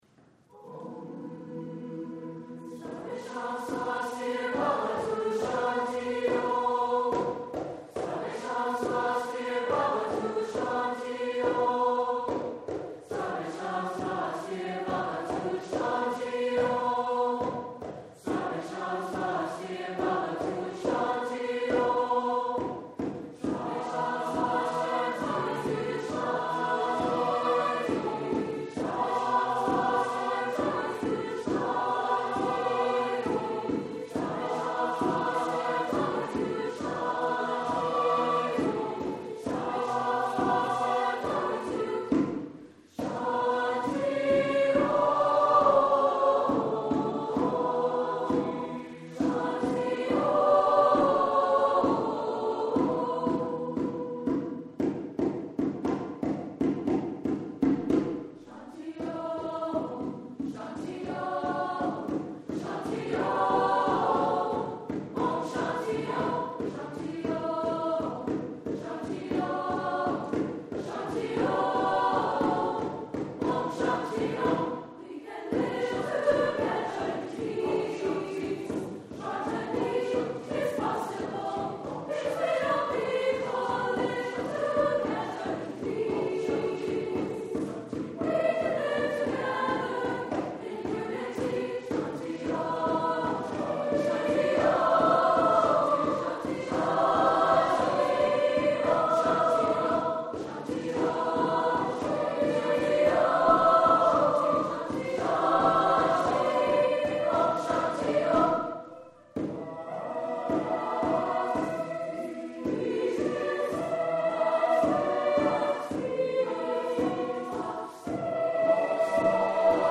Voicing: SSATB,Tabla and Harmonium